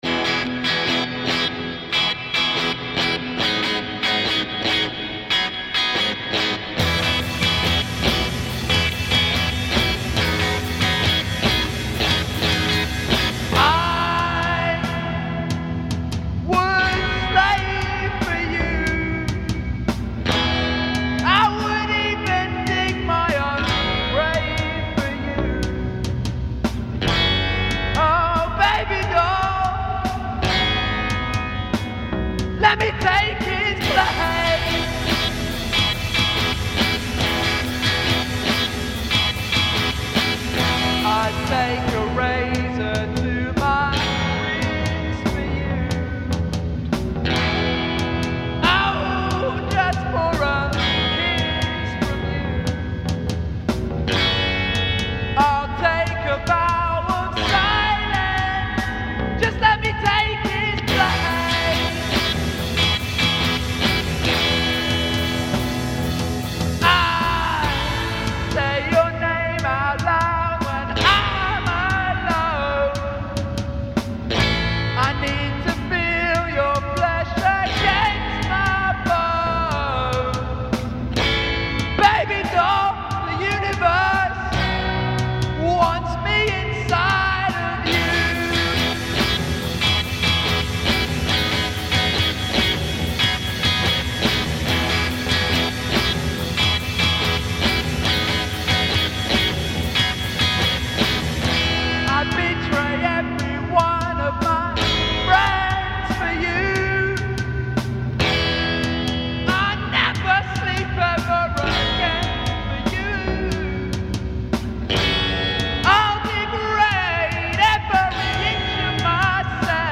Heartfelt garage proto-punk.